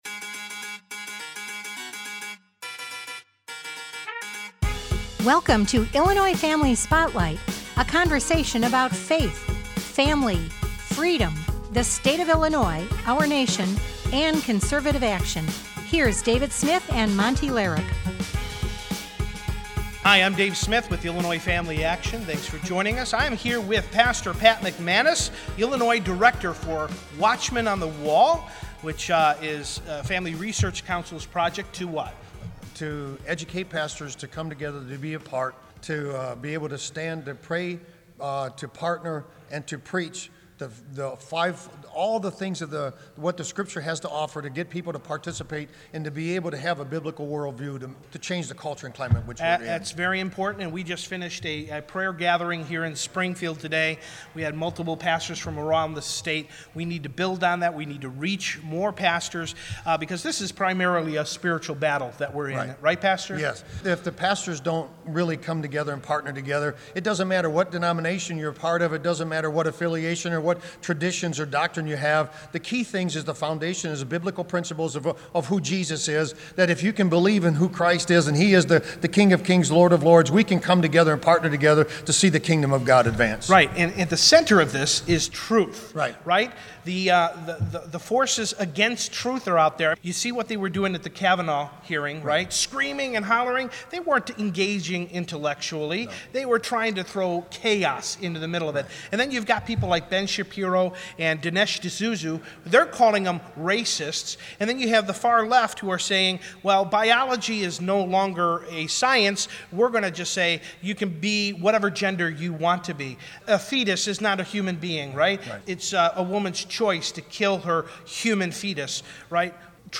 at a recent IFI prayer event in Springfield.